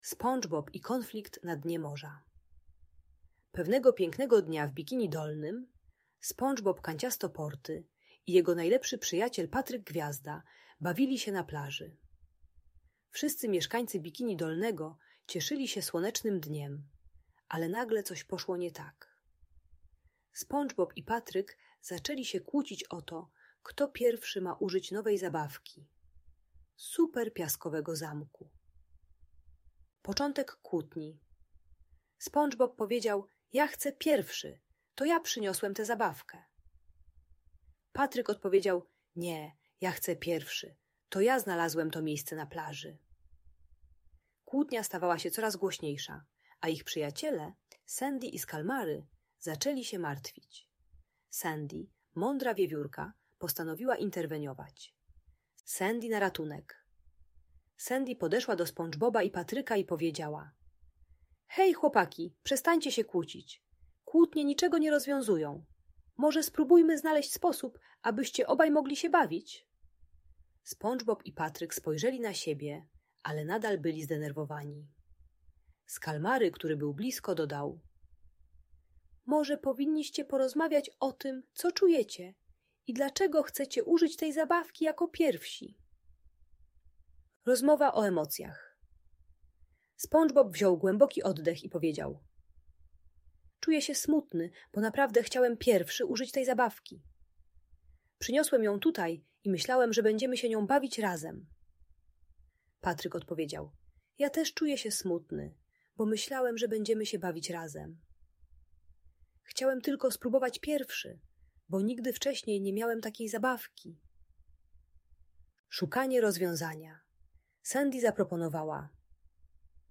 Ta audiobajka o rozwiązywaniu konfliktów uczy techniki dzielenia się czasem i rozmowy o emocjach zamiast kłótni. Pomaga dziecku zrozumieć, jak spokojnie rozwiązywać spory z rodzeństwem i kolegami.